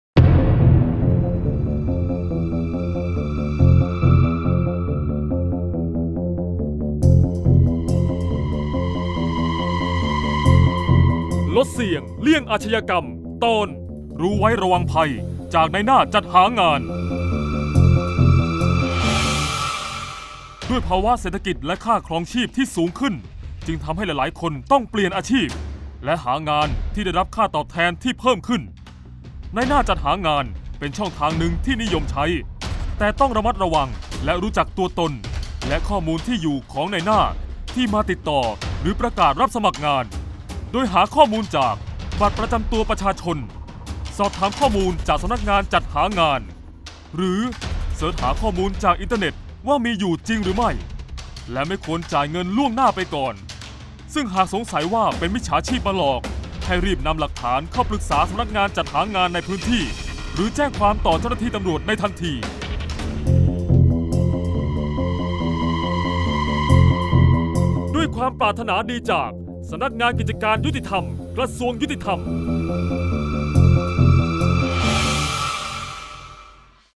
เสียงบรรยาย ลดเสี่ยงเลี่ยงอาชญากรรม 06-ระวังภัยนายหน้าจัดหางาน